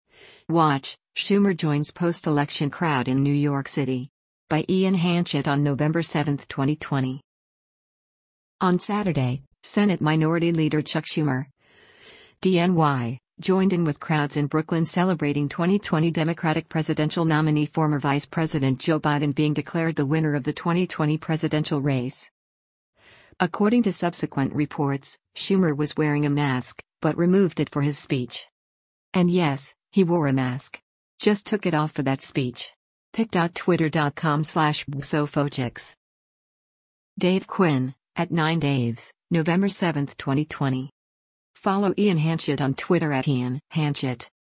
On Saturday, Senate Minority Leader Chuck Schumer (D-NY) joined in with crowds in Brooklyn celebrating 2020 Democratic presidential nominee former Vice President Joe Biden being declared the winner of the 2020 presidential race.
According to subsequent reports, Schumer was wearing a mask, but removed it for his speech.